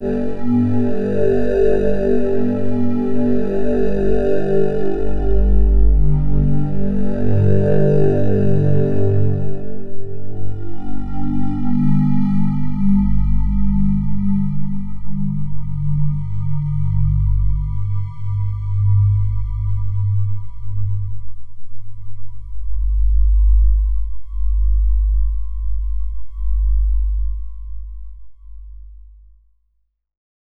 Index of /90_sSampleCDs/Club_Techno/Atmos
Atmos_15_G1.wav